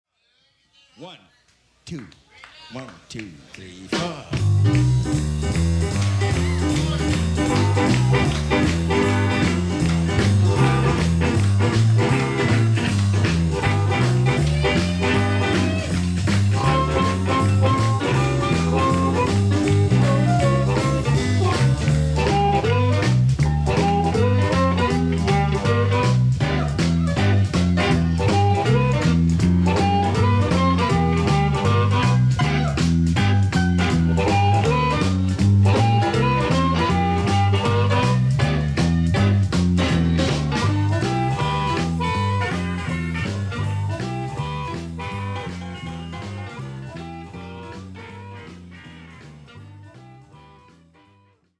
Per complicare ulteriormente il tutto, se accento in modo diveso le terzine del 12/8 ottengo il Boogie woogie un altro ritmo tipico del blues, da cui deriva lo swing, ritmo tipico del jazz.
Boogie woogie (da Caledonia, Muddy Waters)